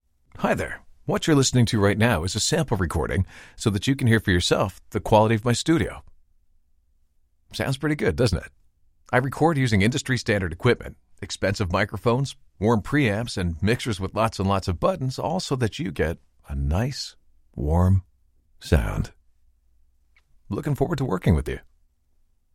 Male
Authoritative, Confident, Conversational, Engaging, Friendly, Natural
Neutral, North American, Standard American Accent, Canadian
Commercial.mp3
Microphone: Rode NT2-A
Audio equipment: Rode NT2-A mic, dbx 286A preamp, Audient iD14 interface, Pro Tools 12, Mac OS X, Presonus E5 XT studio monitors